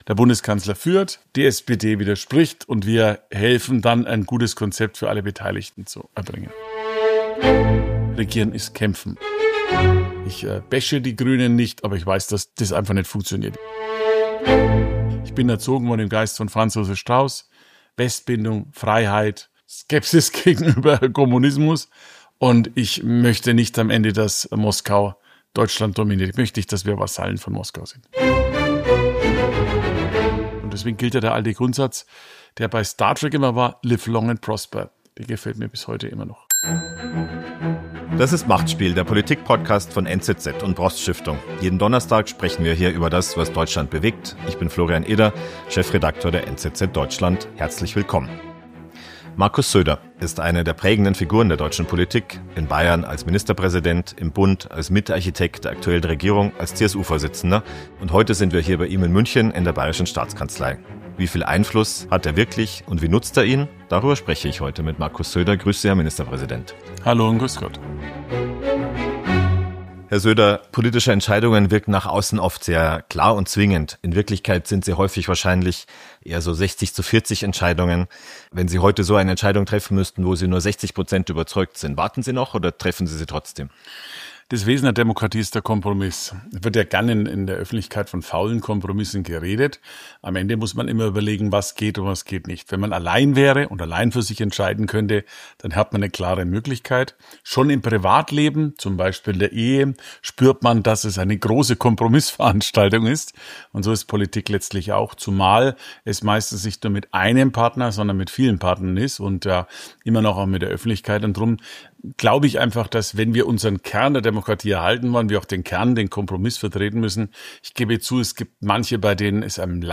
Wie Söder verhindern will, dass die AfD auch in Bayern weiter wächst, welcher Satz aus "Star Trek" ihn bis heute leitet und was Politik mit Judo und Schach zu tun hat - das erzählt er in dieser Folge "Machtspiel". Gast: Markus Söder, CSU-Chef und Ministerpräsident von Bayern Host